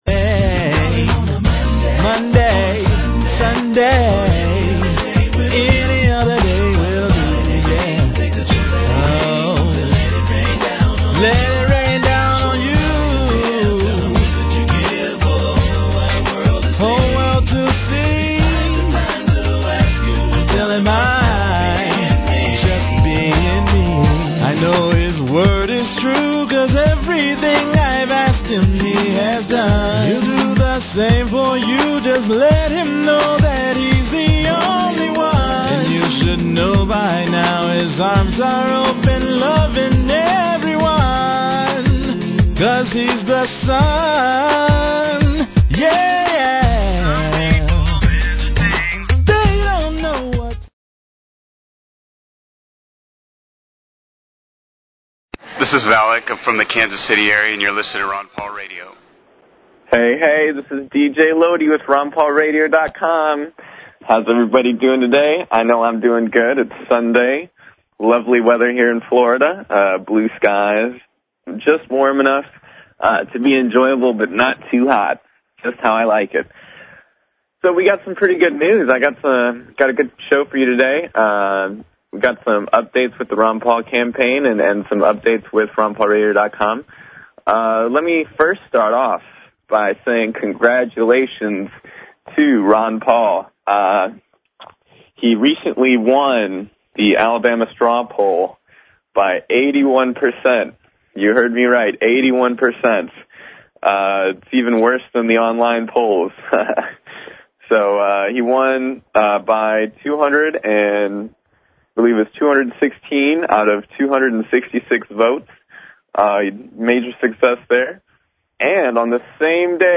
Talk Show Episode, Audio Podcast, Ron_Paul_Radio and Courtesy of BBS Radio on , show guests , about , categorized as
It's a live internet radio call in show for and about the Ron Paul Revolution. It serves as a media outlet for campaign announcements and news, a vehicle for the organization of Ron Paul support, a medium for the dissemination of ideas and tactics and a tool for the mobilization of large numbers of supporters in the movement to elect Ron Paul as our next President.